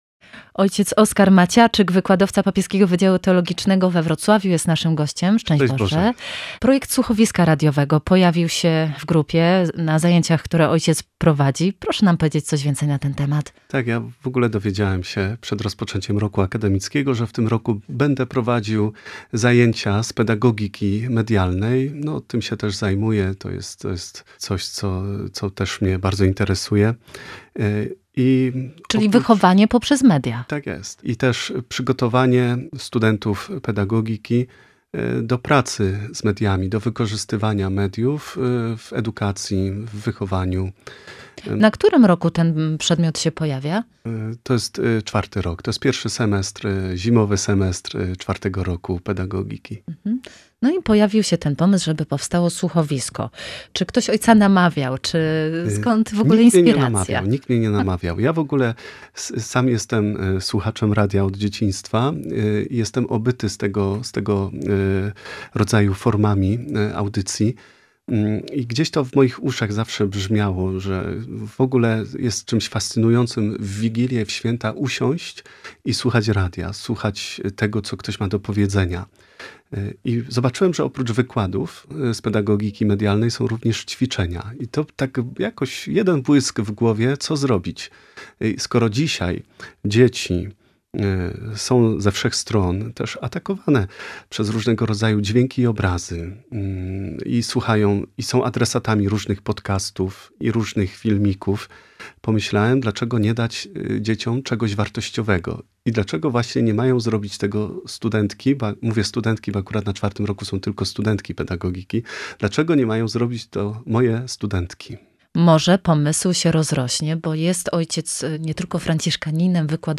Autorki słuchowiska w RR
02_rozmowa.mp3